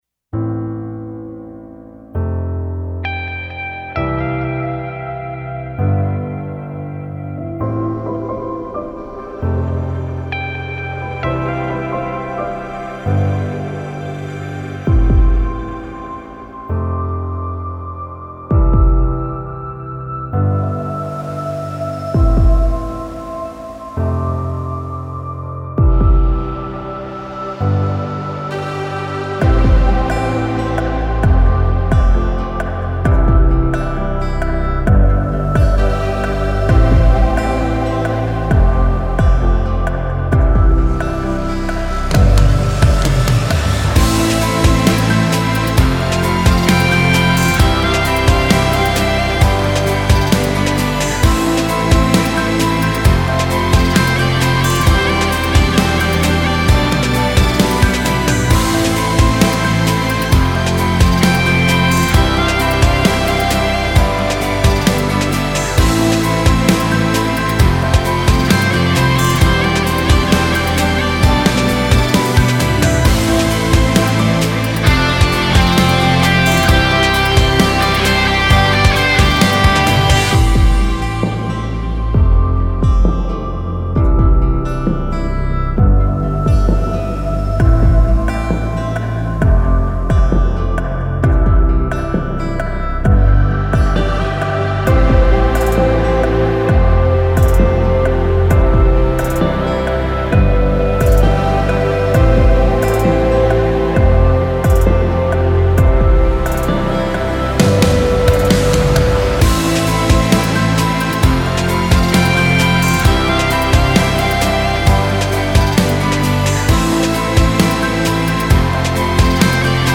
Характер песни: серьёзный, лирический.
Темп песни: средний.
• Минусовка